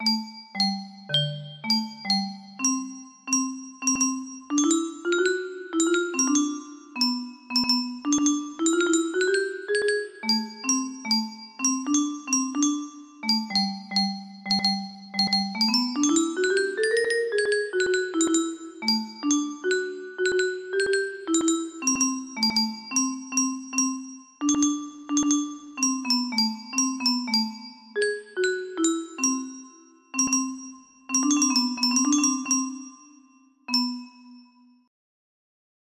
Little Rain music box melody